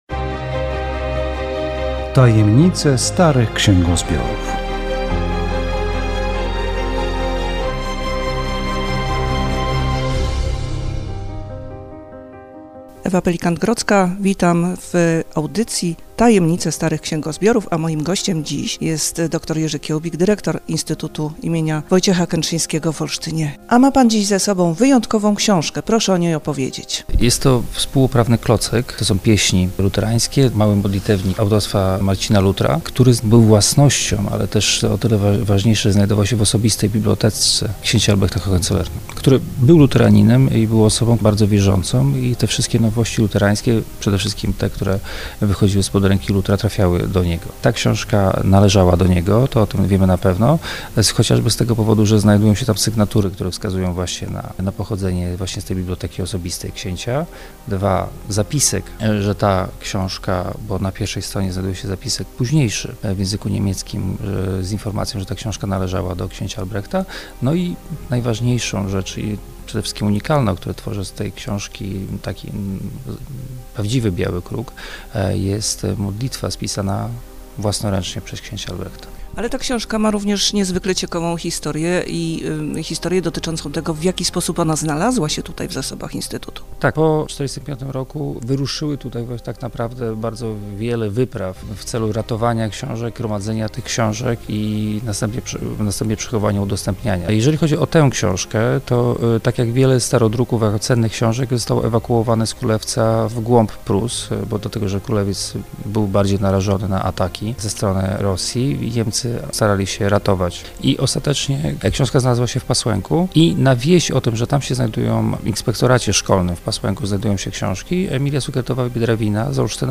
Audycja radiowa "Tajemnice starych księgozbiorów".